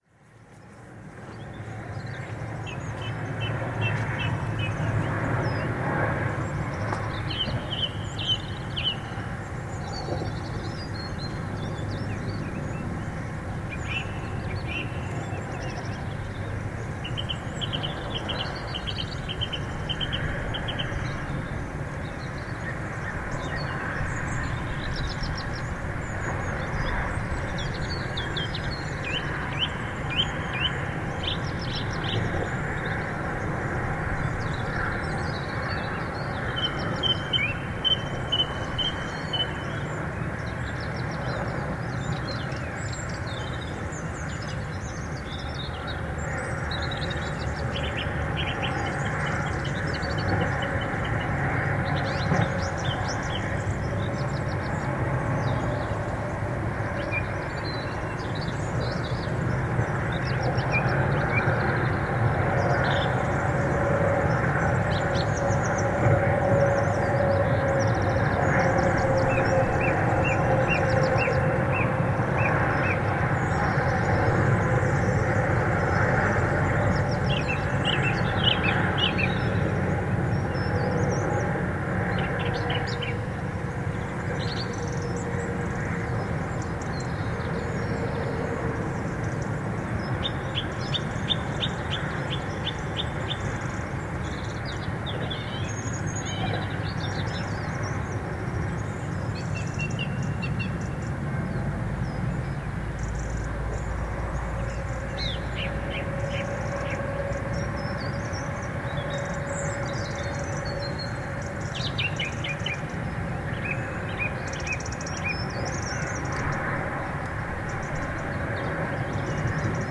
Som dos pássaros nos campos enquanto passam carros na autoestrada. Gravado com Fostex FR-2LE e um par de microfones shotgun Rode NTG-2
Tipo de Prática: Paisagem Sonora Rural
Folgosa-Pássaros-e-a-autoestrada.mp3